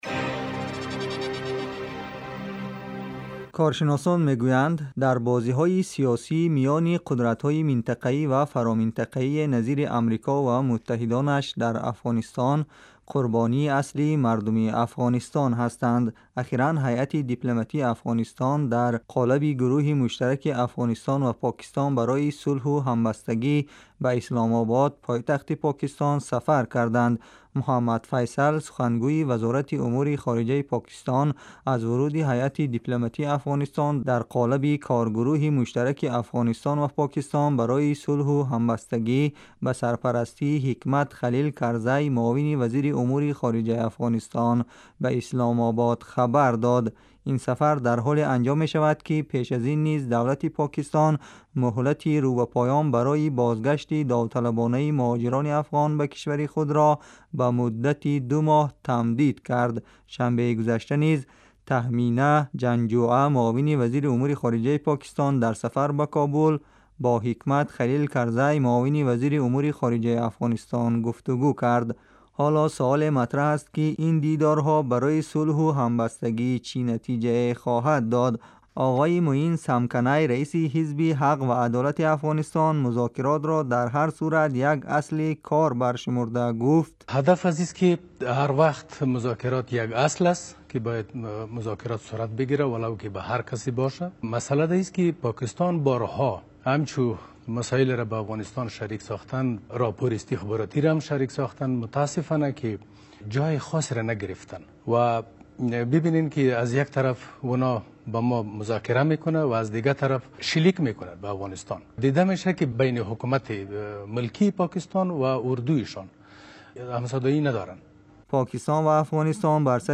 гузориши вижа